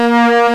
Index of /m8-backup/M8/Samples/Fairlight CMI/IIX/STRINGS1
POLYMOOG.WAV